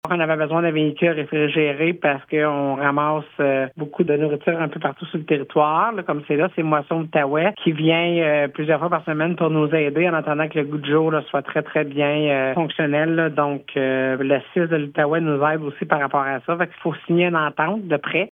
La préfète de la MRCVG, Chantal Lamarche, explique que l’achat de cette fourgonnette était devenu essentiel :